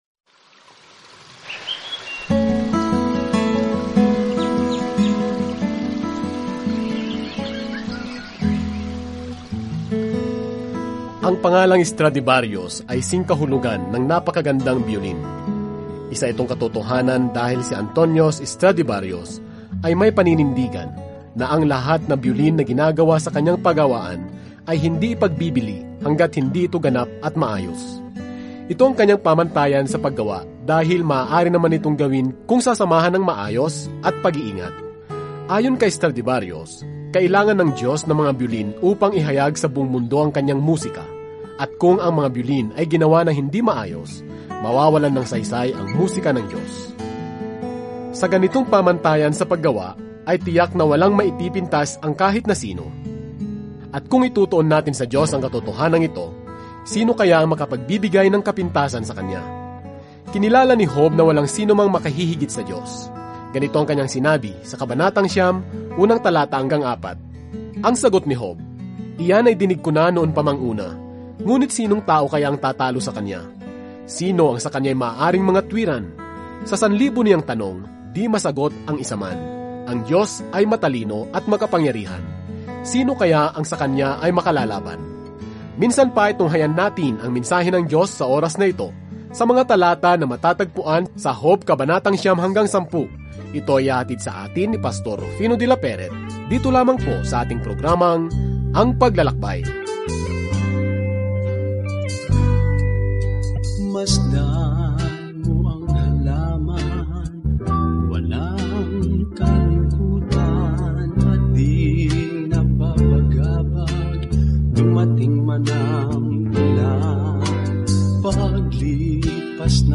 Banal na Kasulatan Job 9 Job 10 Araw 7 Umpisahan ang Gabay na Ito Araw 9 Tungkol sa Gabay na ito Sa dramang ito sa langit at lupa, nakilala natin si Job, isang mabuting tao, na pinahintulutan ng Diyos na salakayin ni Satanas; lahat ay may napakaraming katanungan sa paligid kung bakit nangyayari ang mga masasamang bagay. Araw-araw na paglalakbay sa Job habang nakikinig ka sa audio study at nagbabasa ng mga piling talata mula sa salita ng Diyos.